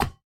Minecraft Version Minecraft Version 25w18a Latest Release | Latest Snapshot 25w18a / assets / minecraft / sounds / block / crafter / craft.ogg Compare With Compare With Latest Release | Latest Snapshot
craft.ogg